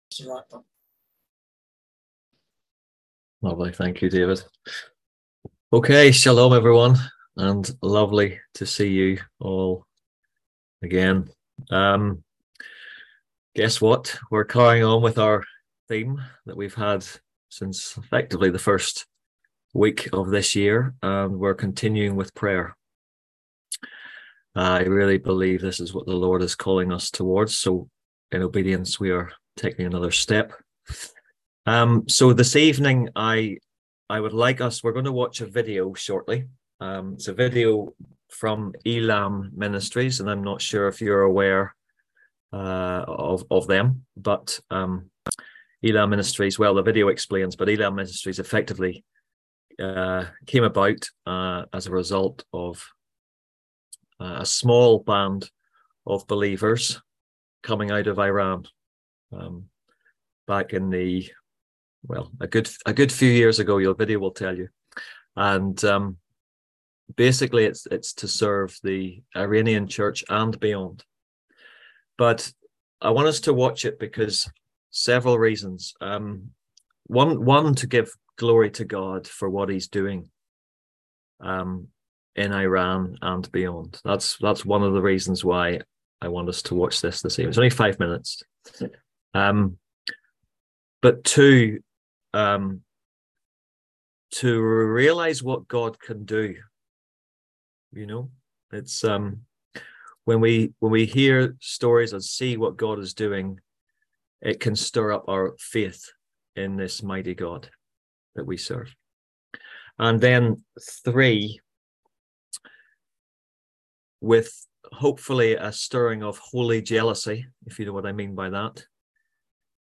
On June 26th at 7pm – 8:30pm on ZOOM